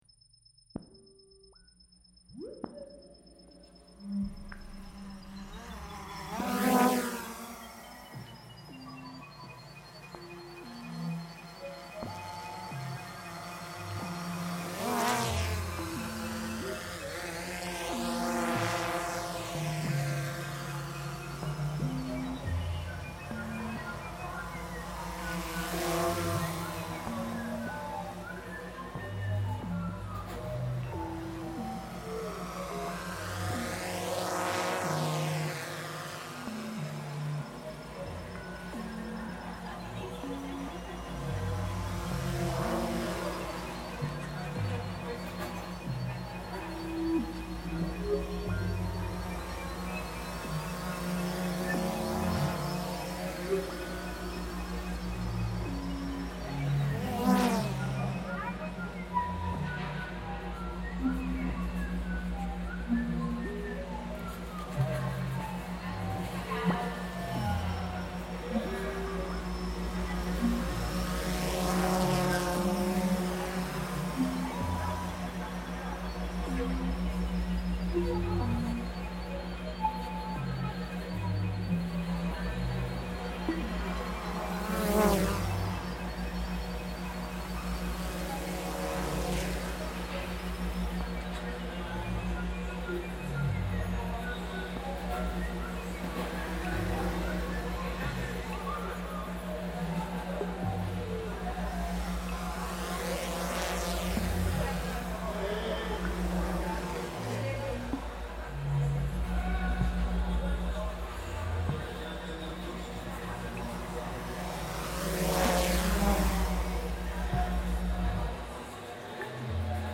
Florence market reimagined